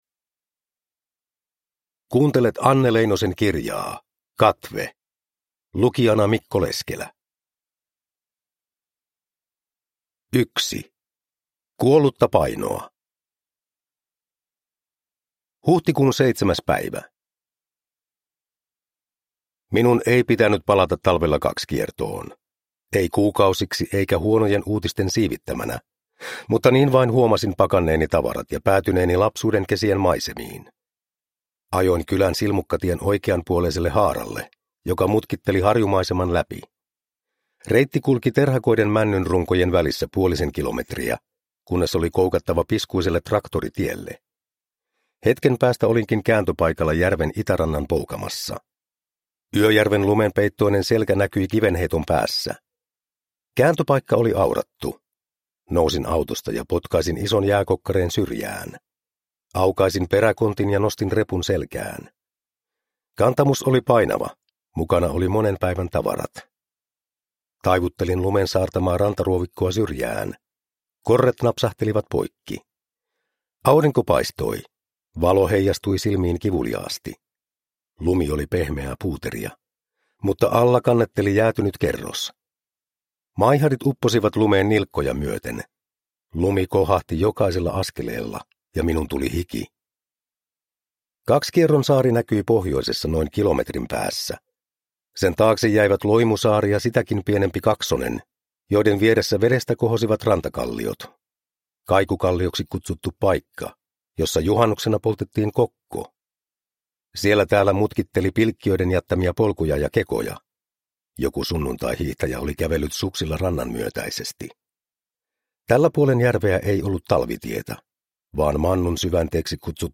Katve – Ljudbok